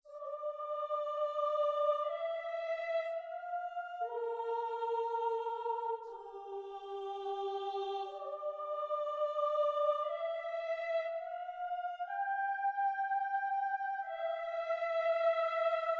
黑暗合唱团
描述：方舟合唱团在FL studio 12中制作。
Tag: 120 bpm Trap Loops Choir Loops 2.69 MB wav Key : Unknown